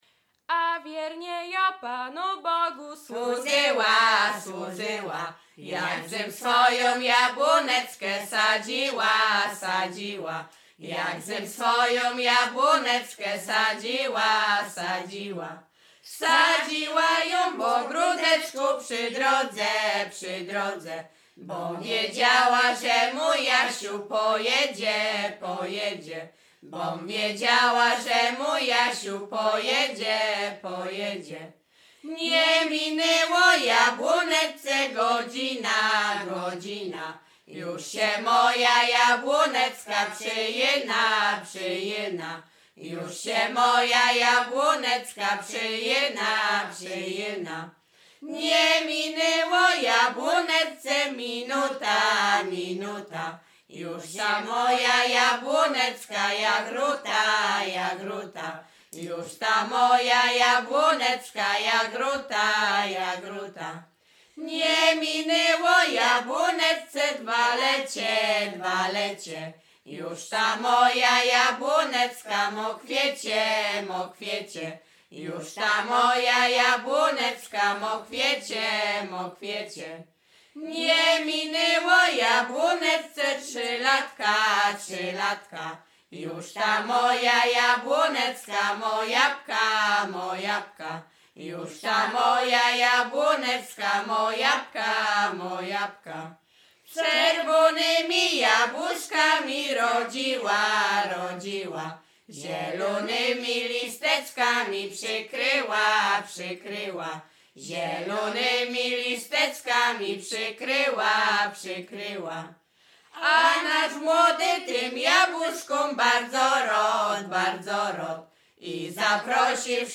Śpiewaczki z Mroczek Małych
województwo łodzkie, powiat sieradzki, gmina Błaszki, wieś Mroczki Małe
Weselna